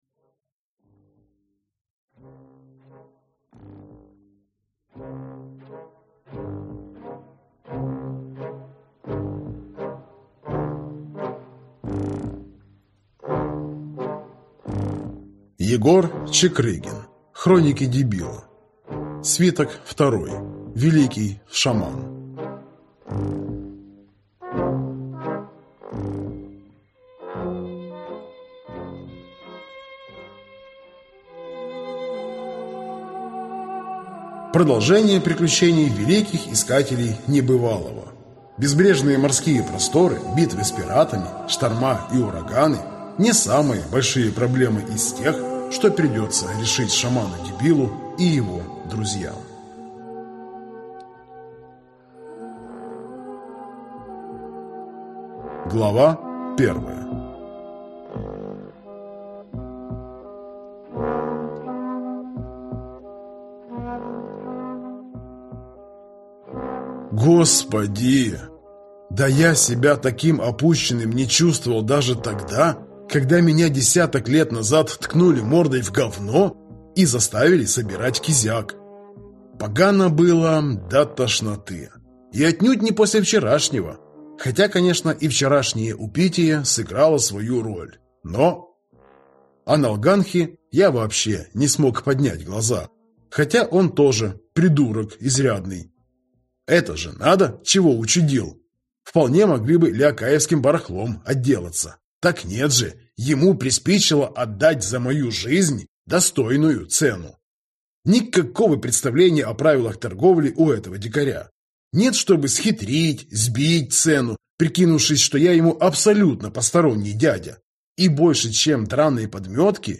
Aудиокнига Хроники Дебила.